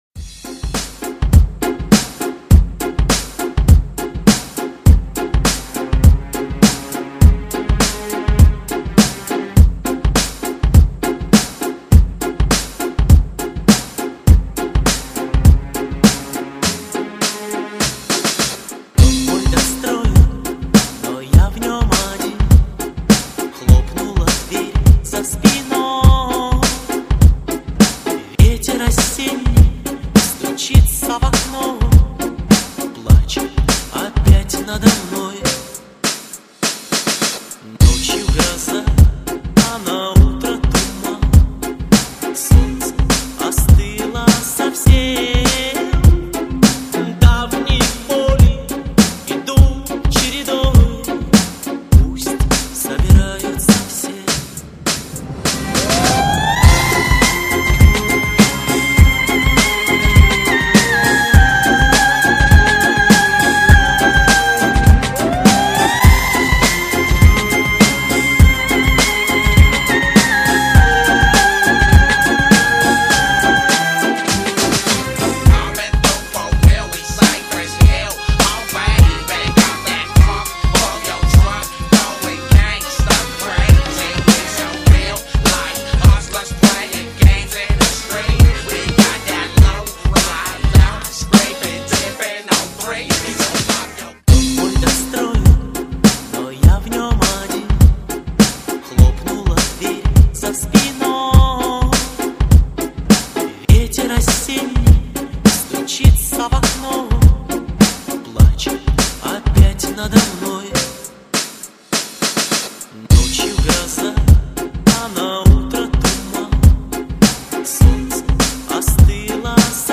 类型: 电音炫音
全球热播经典汽车音乐舞曲，动感的节奏劲爆的音乐沸腾你的血液。